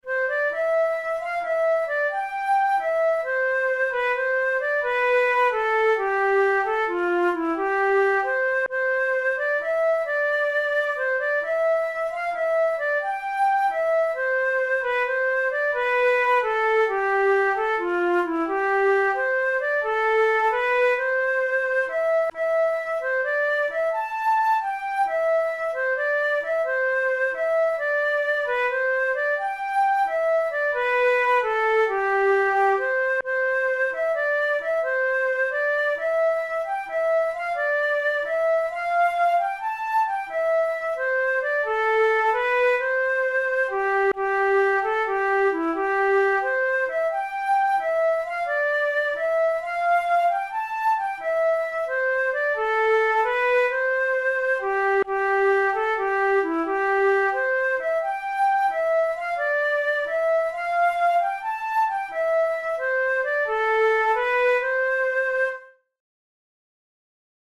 Traditional Scottish song
Whatever the origin of the above melody may have been it has a decidedly Gaelic tonality.
Categories: Celtic Music Slow airs Traditional/Folk Difficulty: easy